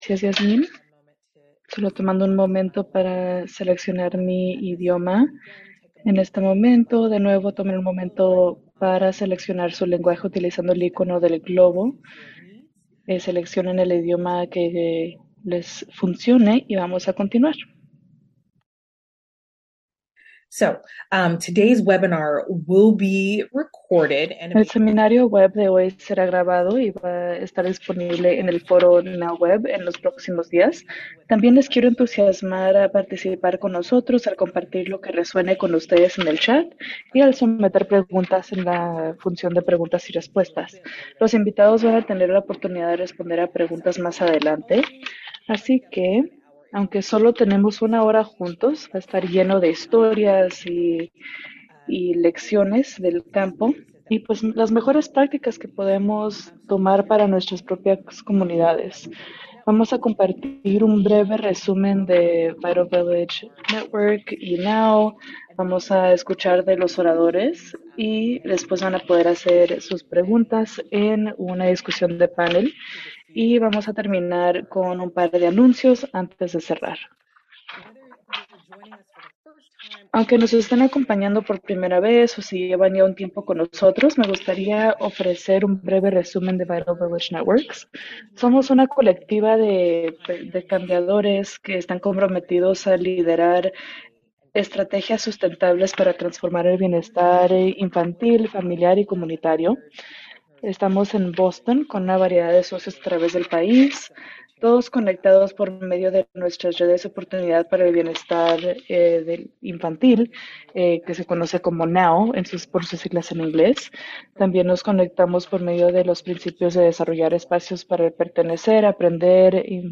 VIRTUAL LEARNING LAB ARCHIVE
Los oradores destacarán las estrategias que las coaliciones locales han llevado a defender la legislación y el cambio de políticas, centrar la voz de la comunidad y crear redes de apoyo.